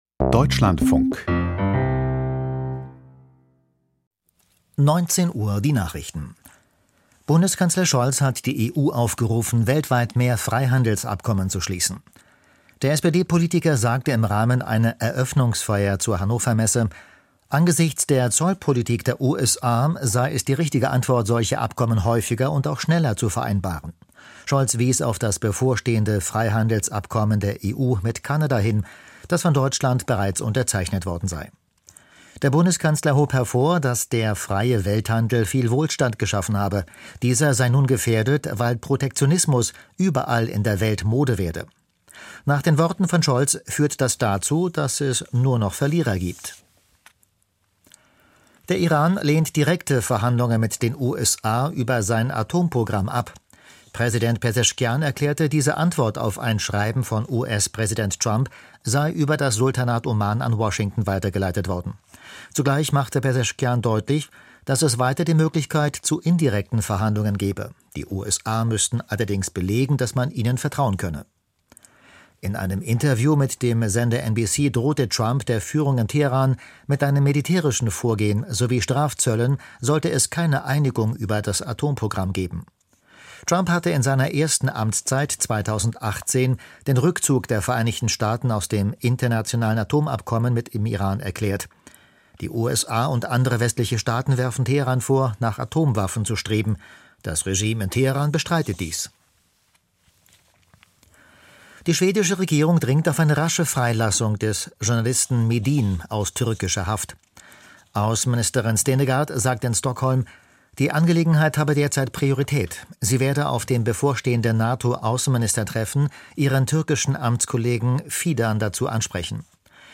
Die Nachrichten